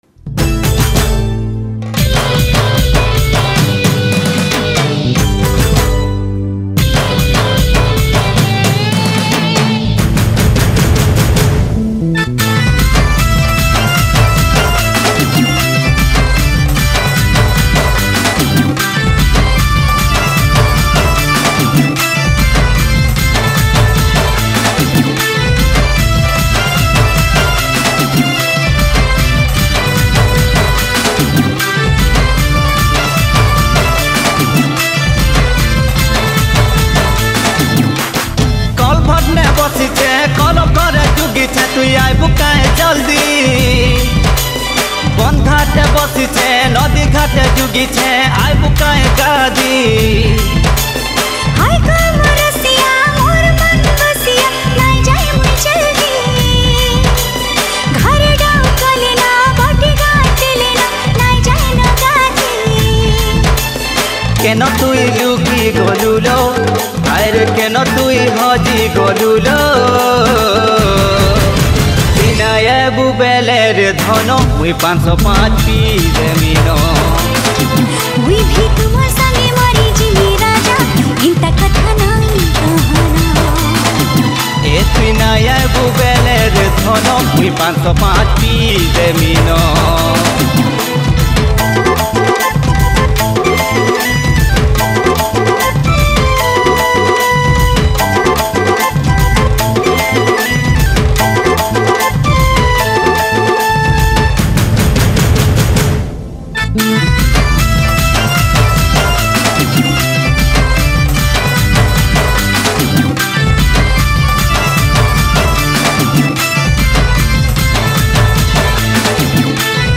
New Sambalpuri Song